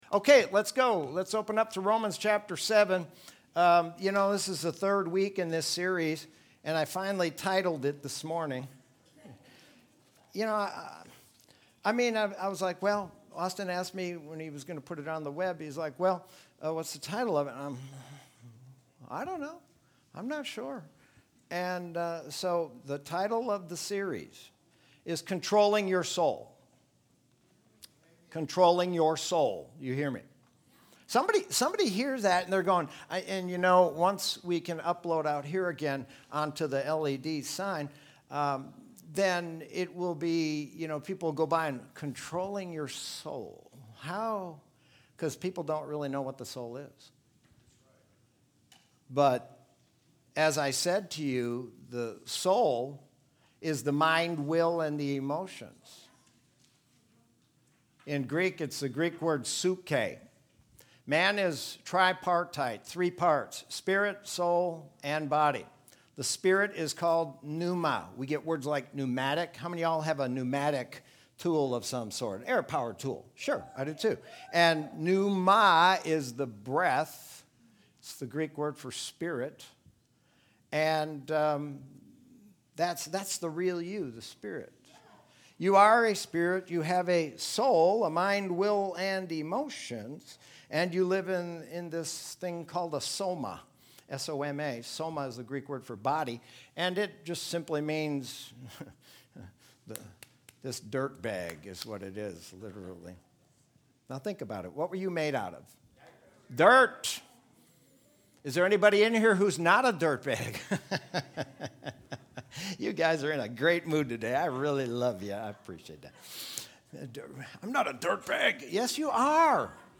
Sermon from Sunday, August 9th, 2020.